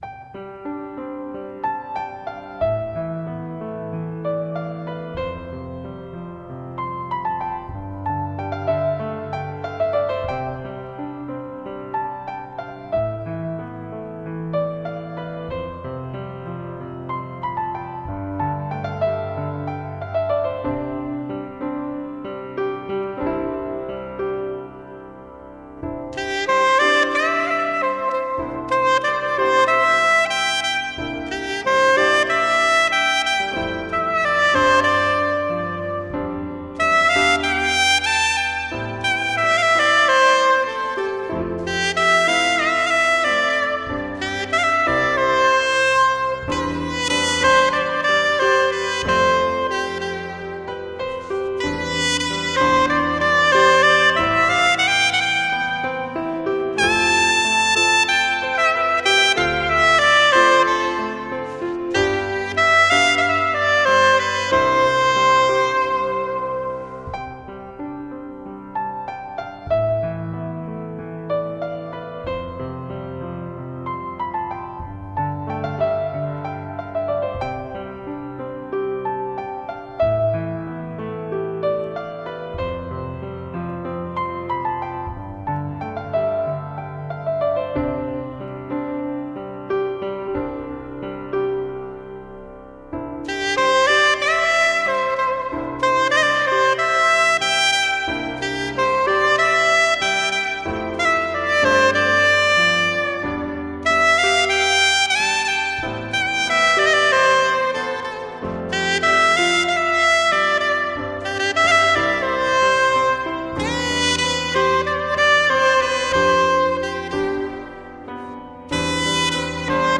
萨克斯风演奏经典流行曲，通透自然的录音，缠绵醉人的演奏。